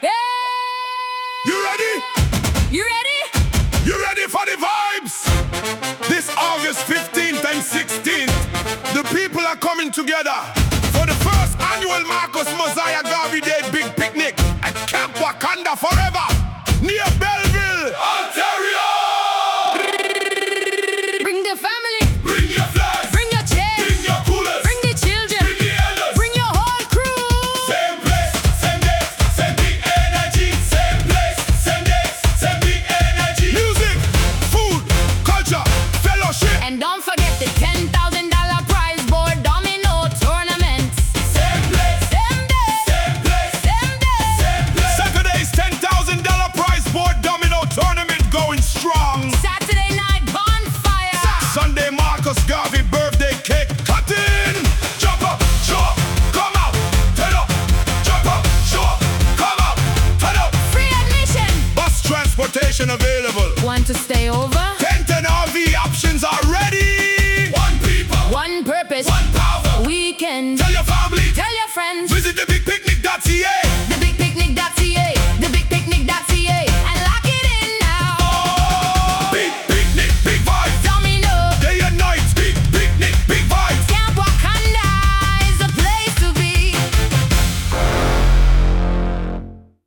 The-Big-Picnic-Reggae-version.mp3